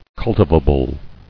[cul·ti·va·ble]